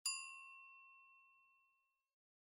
chime.mp3